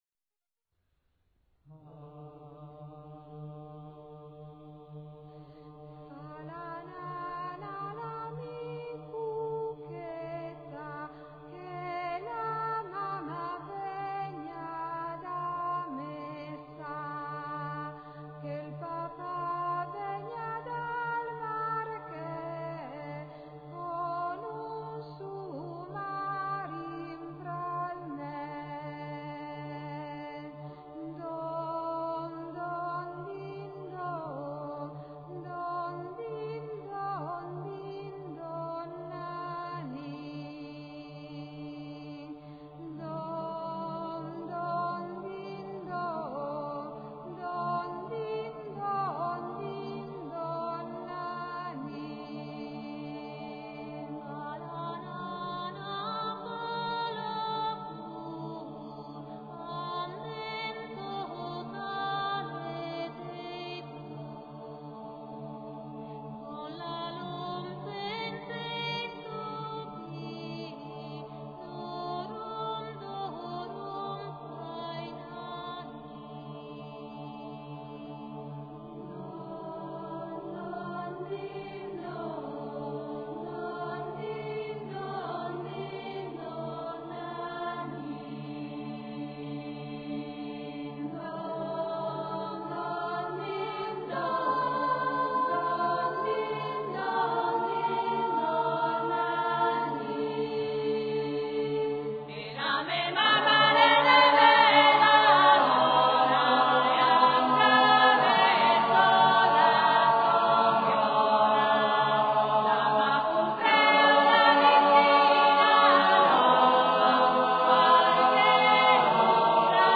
[ voci miste ]